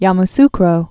(yäm-skrō)